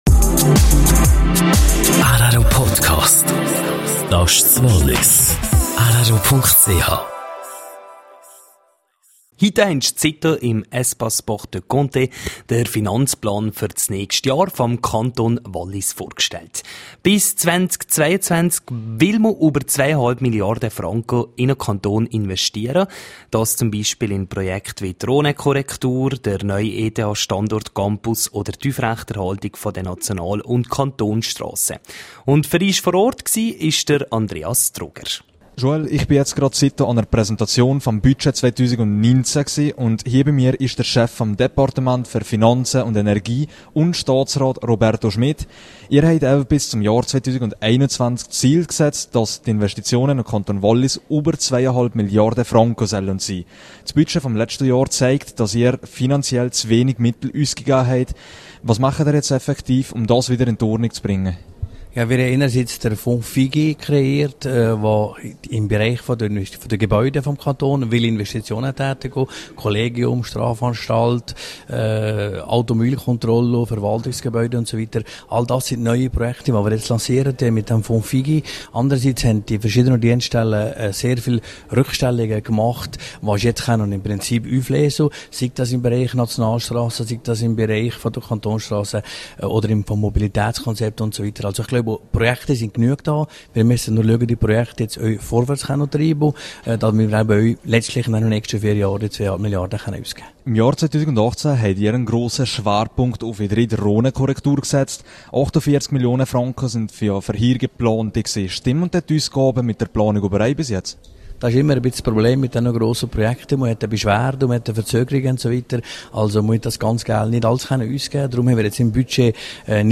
Budgetentwurf 2019: Interview mit Staatsrat Roberto Schmidt.